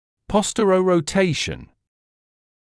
[ˌpɔstərərəu’teɪʃn][ˌпостэрэроу’тэйшн]задненаправленная ротация (о нижней челюсти или других анатомическом структурах)